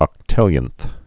(ŏk-tĭlyənth)